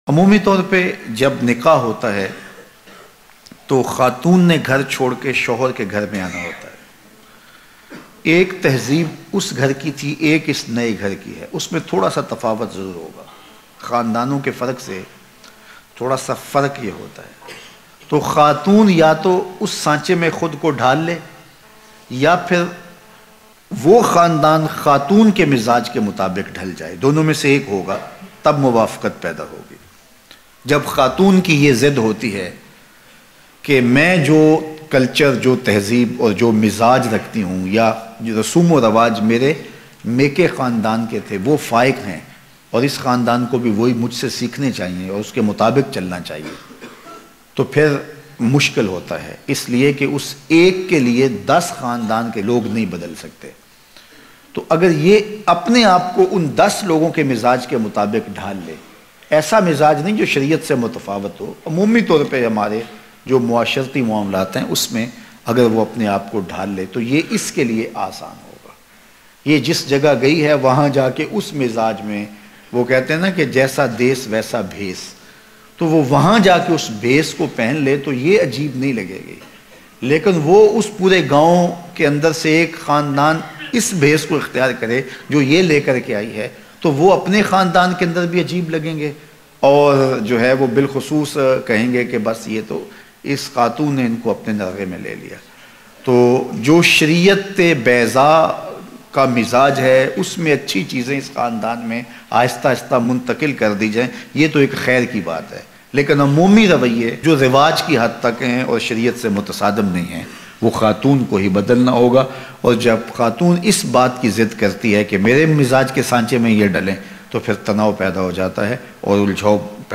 Agr Shohar MAA se milne ki ijazat ne de Bayan